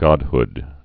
(gŏdhd)